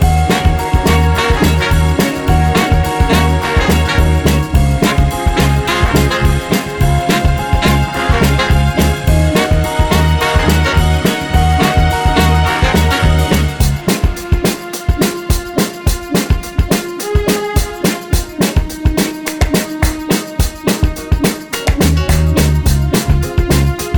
Two Semitones Down Pop (2000s) 3:36 Buy £1.50